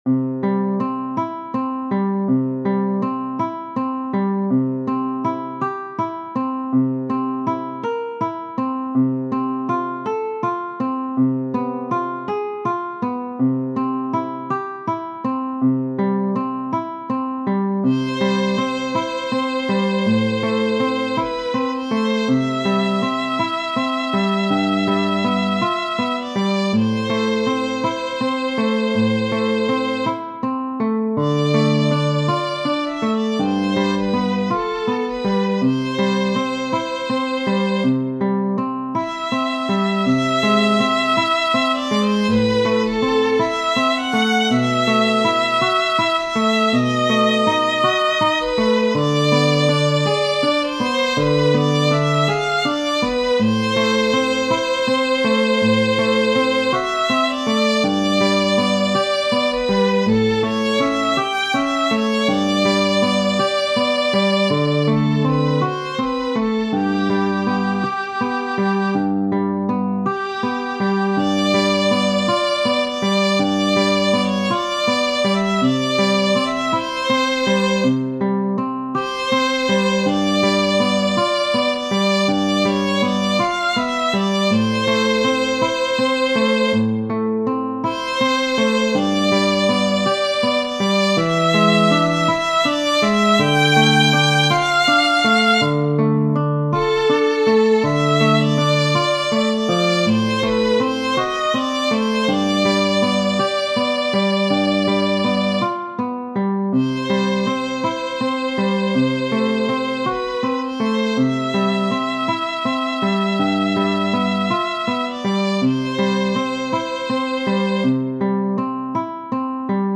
Schubert, F. Genere: Religiose Ave Maria, gratia plena, Maria, gratia plena, Maria, gratia plena, Ave, Ave, Dominus, Dominus tecum.